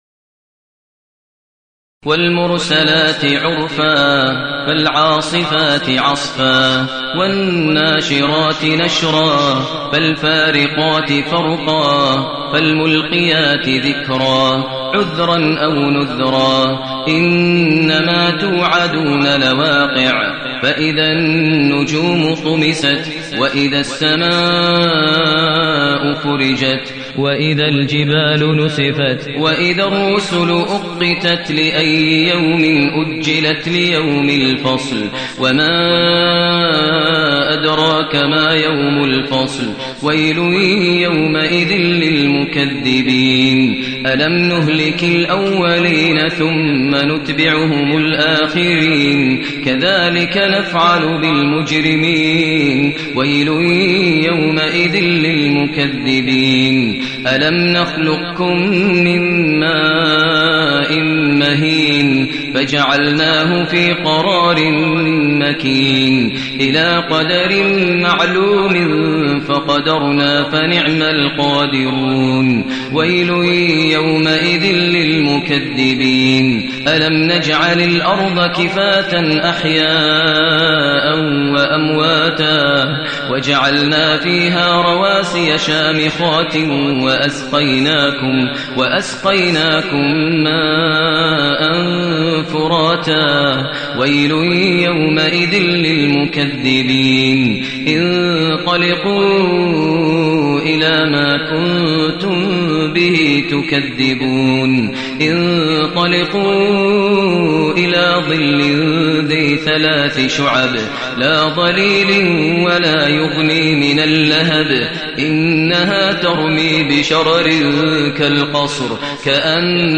المكان: المسجد النبوي الشيخ: فضيلة الشيخ ماهر المعيقلي فضيلة الشيخ ماهر المعيقلي المرسلات